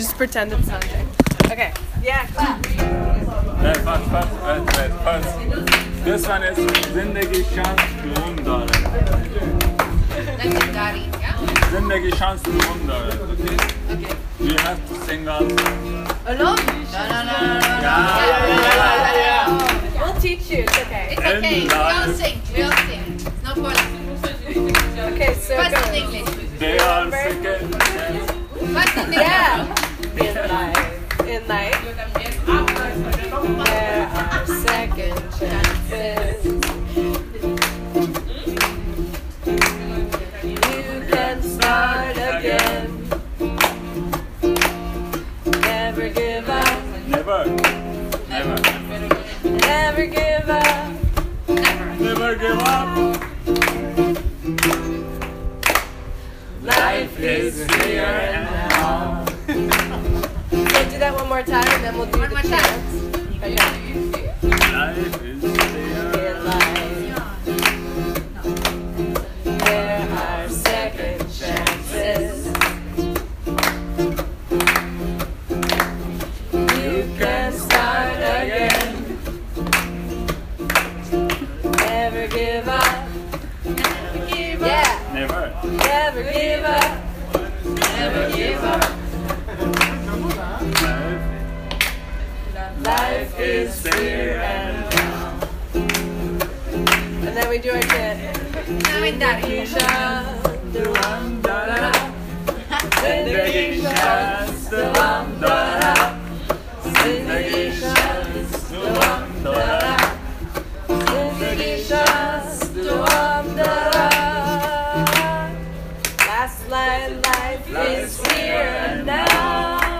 These are recordings from the center that show the process of writing the song:
This is the final recording from the day we wrote the chorus for the song: